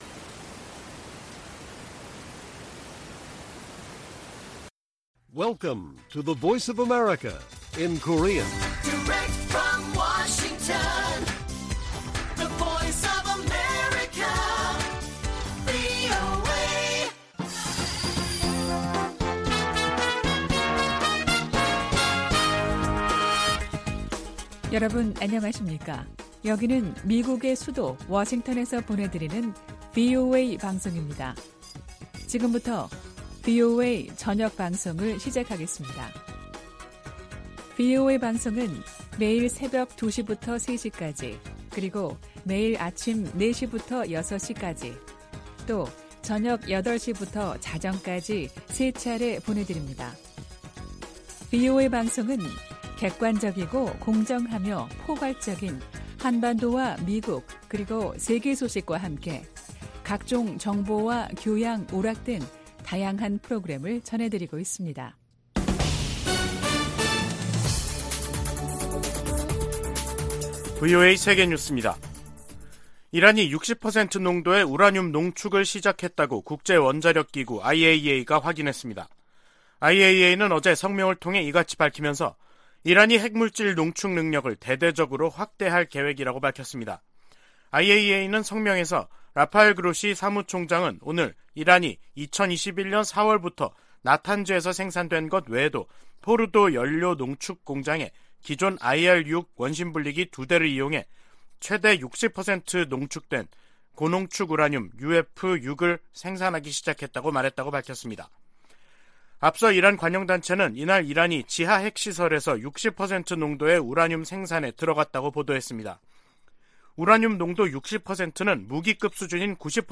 VOA 한국어 간판 뉴스 프로그램 '뉴스 투데이', 2022년 11월 23일 1부 방송입니다. 백악관의 존 커비 전략소통조정관은 중국이 북한에 도발적인 행동을 멈추도록 할 수 있는 압박을 가하지 않고 있다고 지적했습니다. 중국이 북한 불법무기 프로그램 관련 유엔 안보리 결의를 전면 이행해야 한다고 미 국방장관이 촉구했습니다.